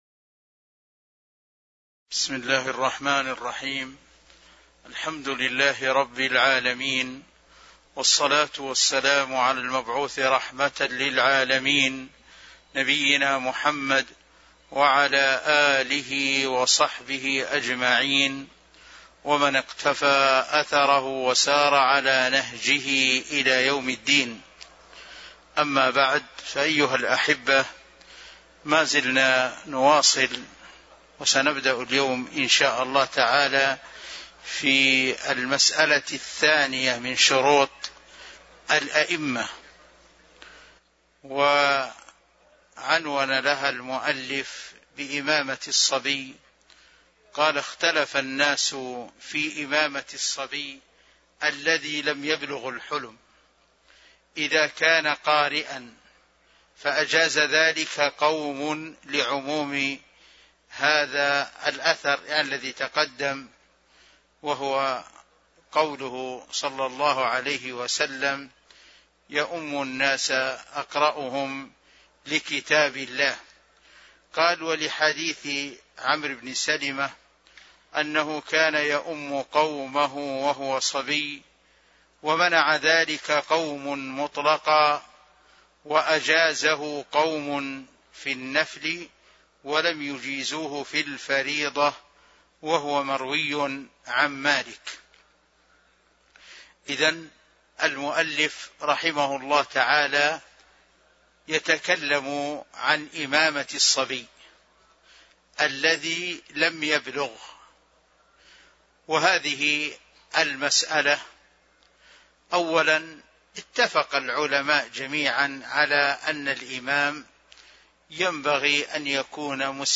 تاريخ النشر ١٩ صفر ١٤٤٣ هـ المكان: المسجد النبوي الشيخ